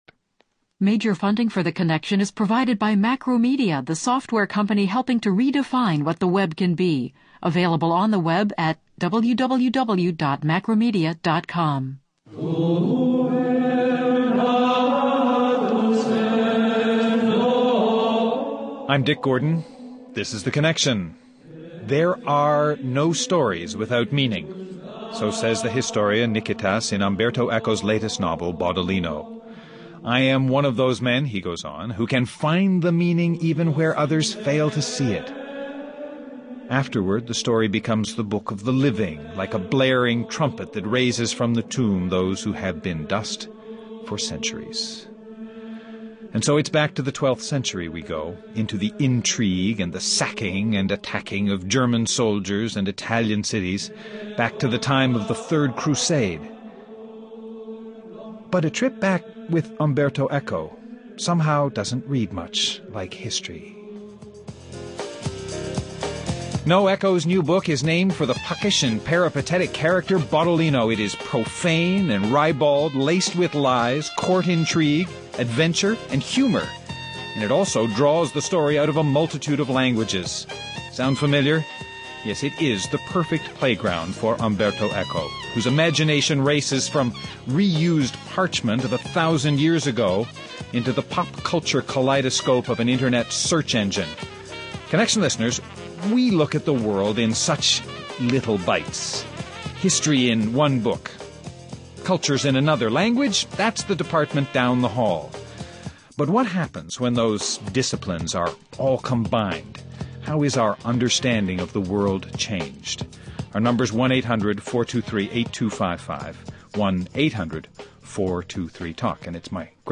Guests: Umberto Eco, a professor of Semiotics at the University of Bologna, and a writer of fiction, essays, academic texts, and children’s books.